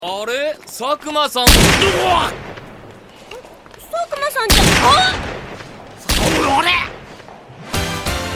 K shooting at people o_O:: duration: 0:08
kgunshots.mp3